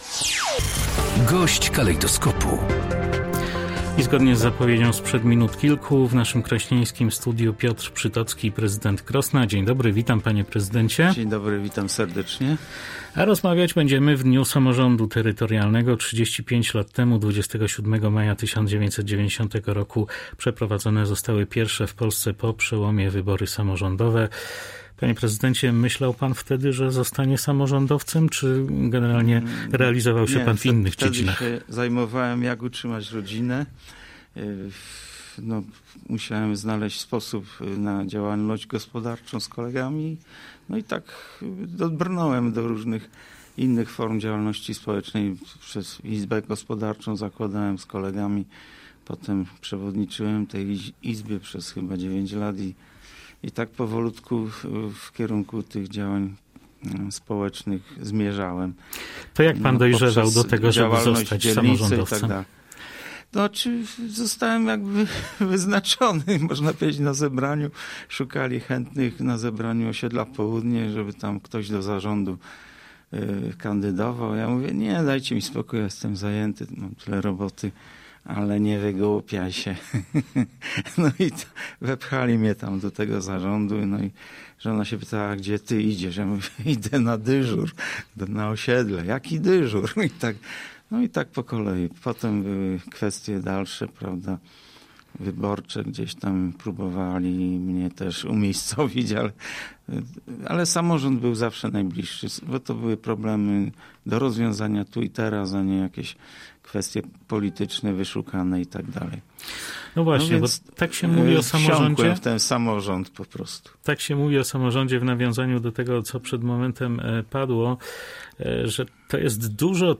– powiedział na naszej antenie Piotr Przytocki, prezydent Krosna, który w 35. rocznicę pierwszych wolnych i demokratycznych wyborów samorządowych był gościem Polskiego Radia Rzeszów.